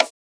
Batmans DarkSoul Perc 2.wav